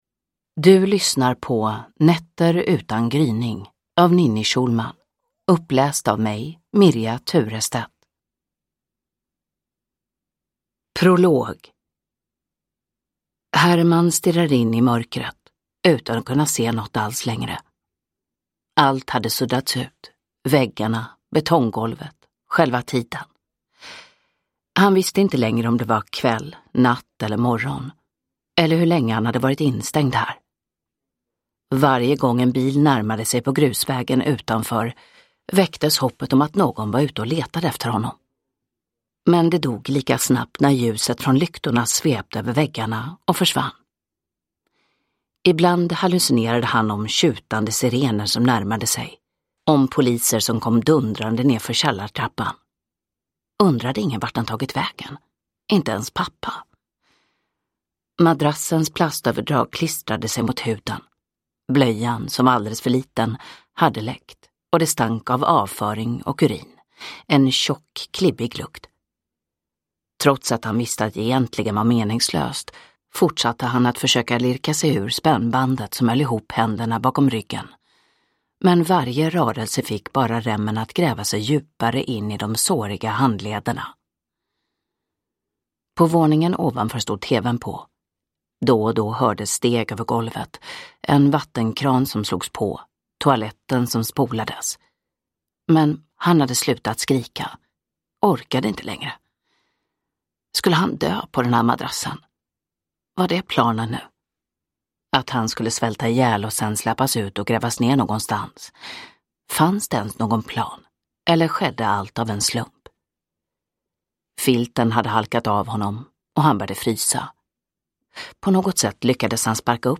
Nätter utan gryning (ljudbok) av Ninni Schulman